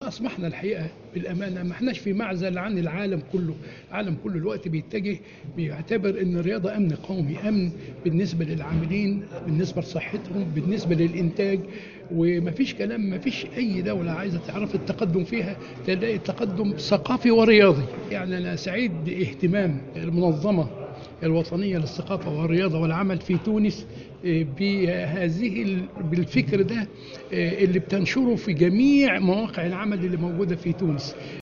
إفتتاح أشغال المؤتمر الوطني 13 للمنظمة الوطنية للثقافة و الرياضة و العمل